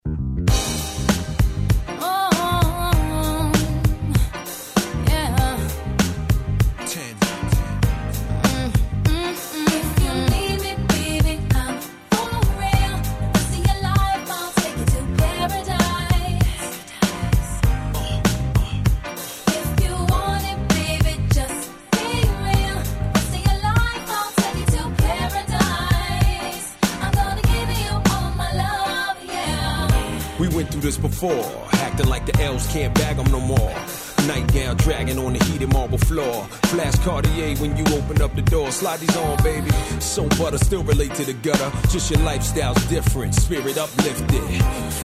02' Smash Hit Hip Hop.